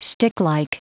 Prononciation du mot sticklike en anglais (fichier audio)
Prononciation du mot : sticklike